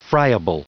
Prononciation du mot friable en anglais (fichier audio)